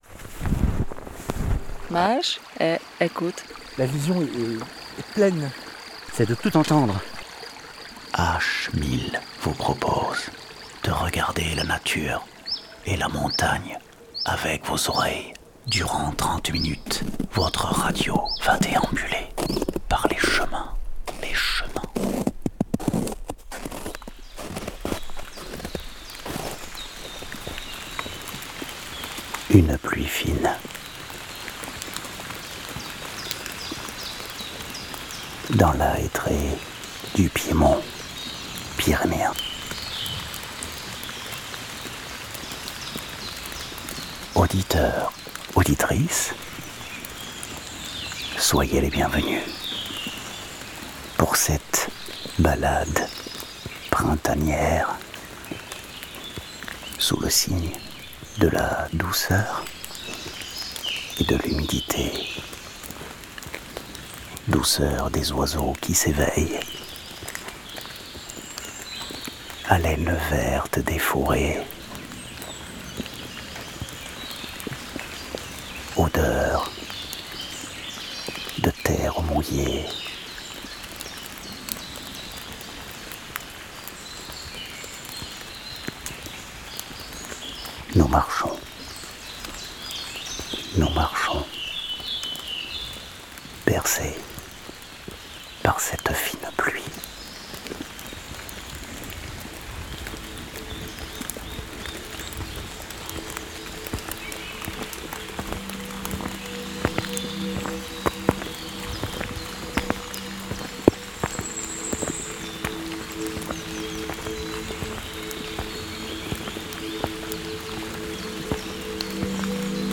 Ondée de pluie dans la forêt
L’épisode invite à écouter la nature autrement, à ressentir la force apaisante de la pluie, la respiration des arbres et la vibration profonde de la forêt. Un moment d’immersion poétique et méditative, à la frontière du réel et de l’imaginaire.